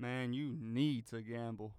MAN you need to gamble.wav